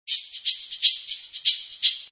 Index of /Habbo_Archive_V2_Wendigo/HabboStuff/Cokestudios Private Server/Cokemusic/src/Mixer Library/Latin Sounds
latinoTwo_marracas00.mp3